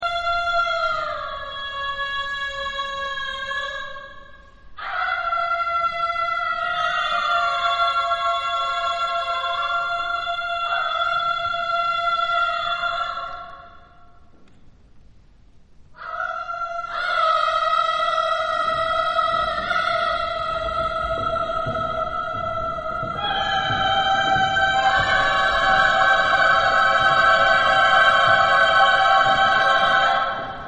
Texto en: Onomatopeya
Género/Estilo/Forma: Vanguardia ; Profano
Tipo de formación coral: SSSSAAAA  (8 voces Coro femenino )
Solistas : Alt (1)  (1 solista(s) )
Instrumentos: ; Palmadas
Tonalidad : libre